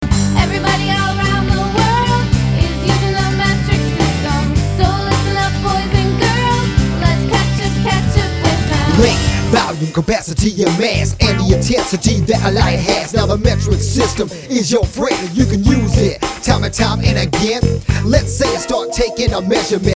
Chants & Raps